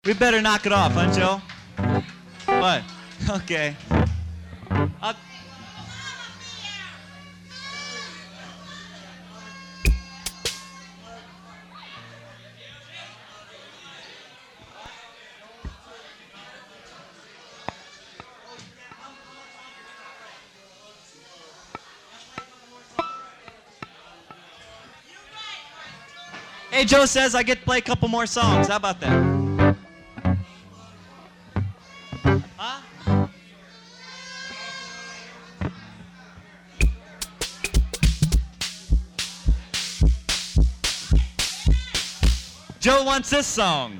Live at Joe's Joint Vol. 2